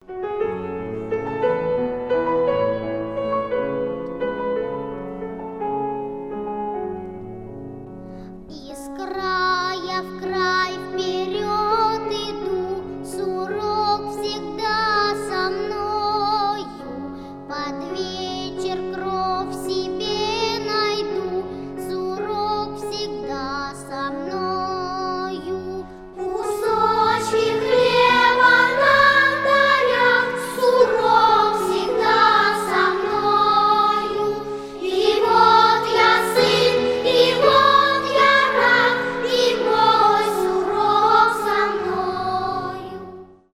грустные , классические
детский голос